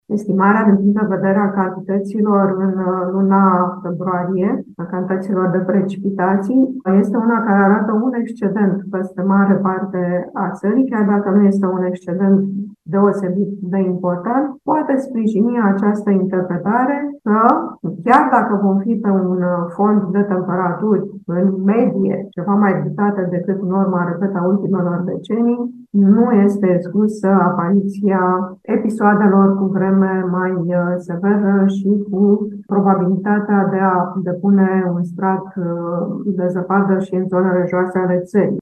Într-o conferință organizată de Focus Energetic, Florinela Georgescu, director executiv al Administrației Naționale de Meteorologie a spus că lunile de iarnă vor fi mai calde decât de obicei, dar în a doua parte a iernii, vom avea ninsori și episoade de ger.